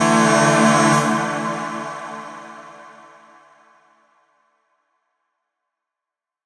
Chords_D_02.wav